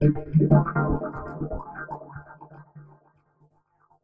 Synth Fx Stab 02.wav